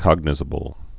(kŏgnĭ-zə-bəl, kŏg-nī-)